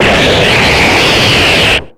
Cri de Kravarech dans Pokémon X et Y.